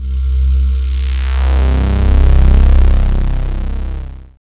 1707L SYNSWP.wav